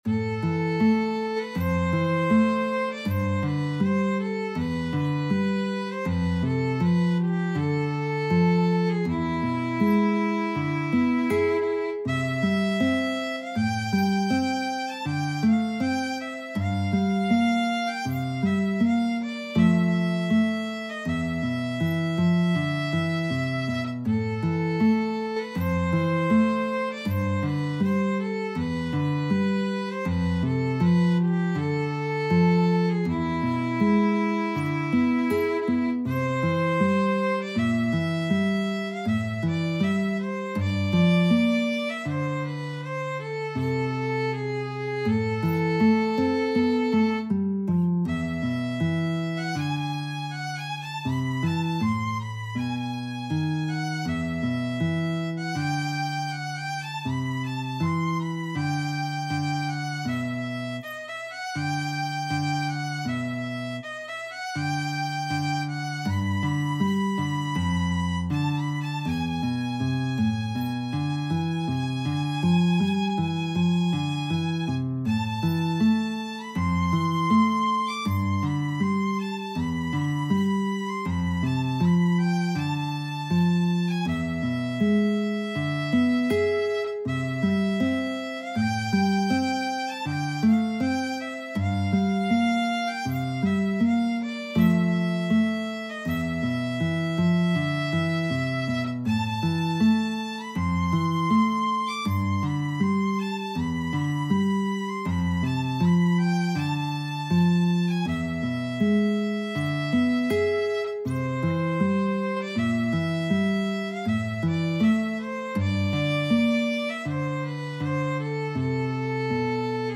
4/4 (View more 4/4 Music)
Andante
Classical (View more Classical Violin-Guitar Duet Music)